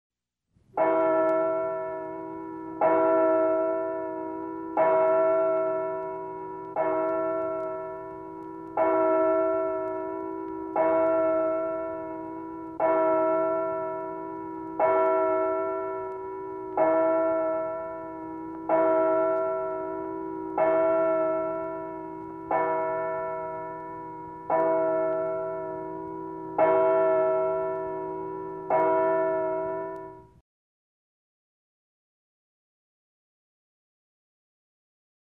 CAMPANA DE IGLESIA
Tonos EFECTO DE SONIDO DE AMBIENTE de CAMPANA DE IGLESIA
Campana_de_Iglesia.mp3